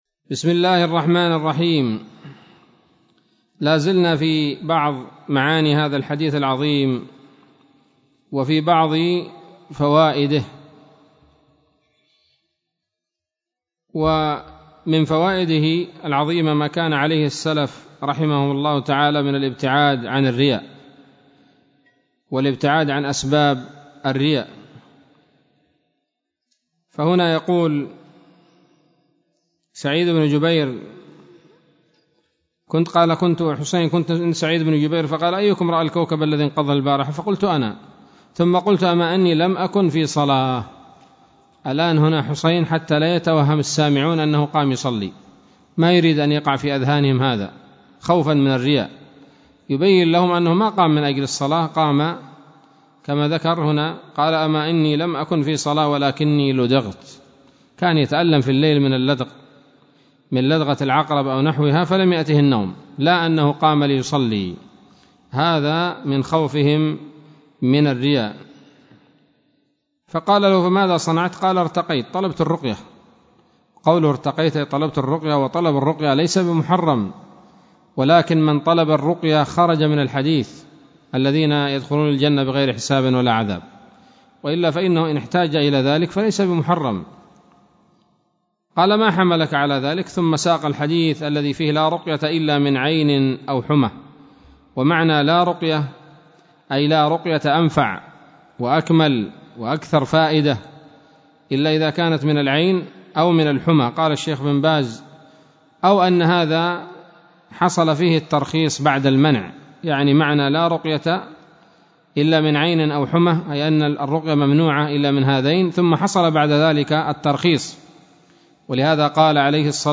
الدرس الثامن من كتاب التوحيد للعام 1441هـ